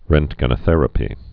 (rĕntgə-nə-thĕrə-pē, -jə-, rŭnt-)